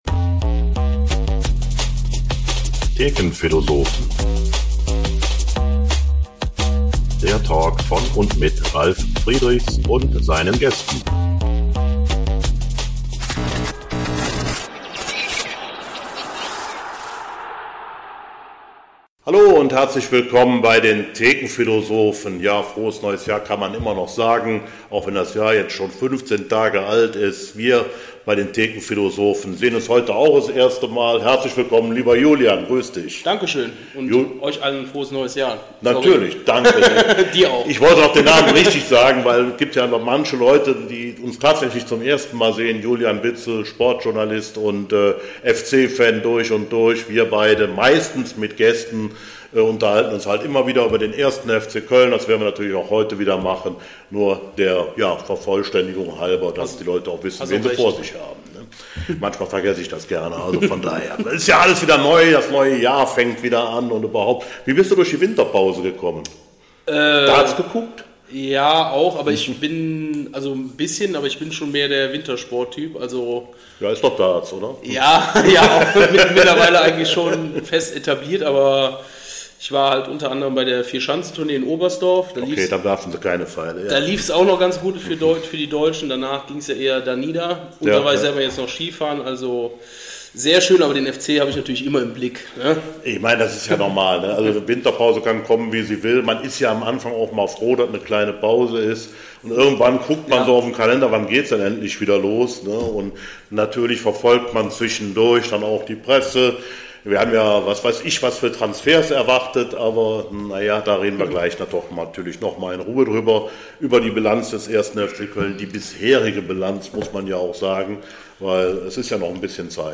PS: Ein Hinweis am Rande: Aufgrund technischer Probleme musste ein Backup-Mikrofon verwendet werden, was die Tonqualität etwas beeinflusst.